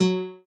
guitar.ogg